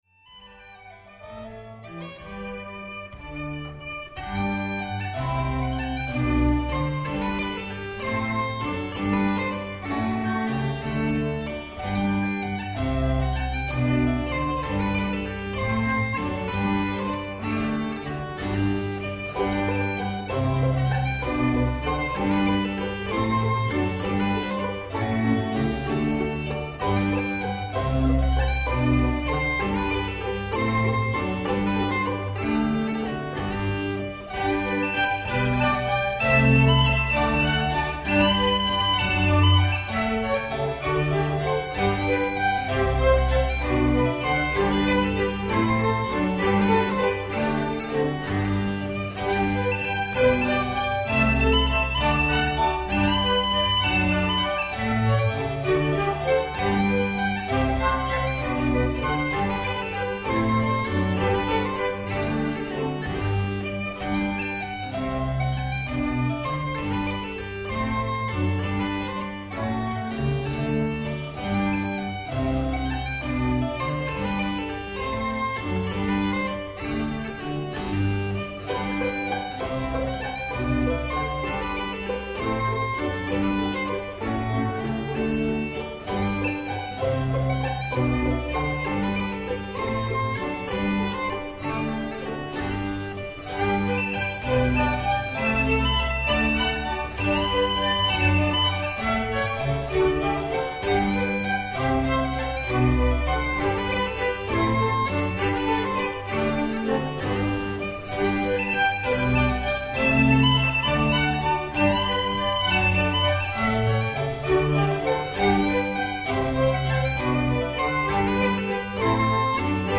Baroque 2000 in St. Olaf',s Durban
This is an excerpt from a few of Turloch O'Carolan's tunes performed with the Baroque 2000 ensemble in St. Olaf's church in Durban South Africa in October 2004 finishing with Bach's minuet in G. The concert was recorded for South Africa FM.
Minuet excerpt.mp3